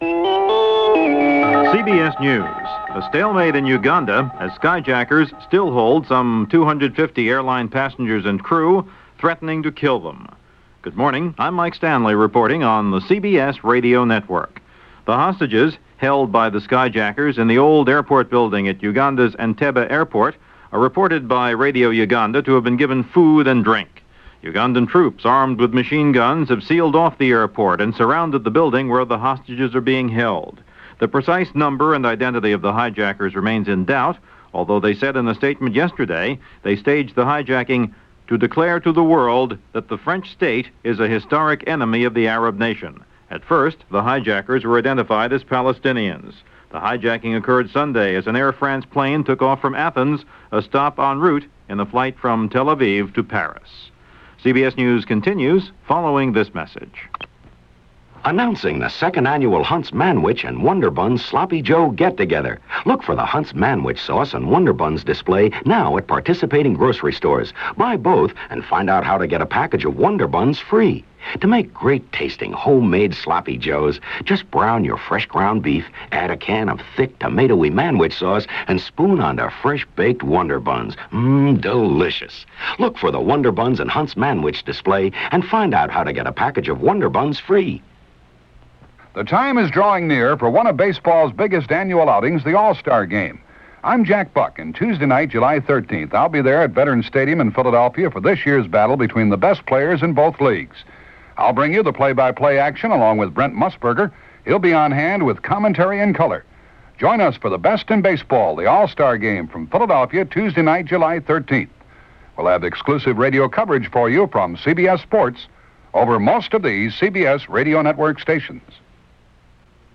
June 29, 1976 – CBS Radio News + Spectrum – Gordon Skene Sound Collection –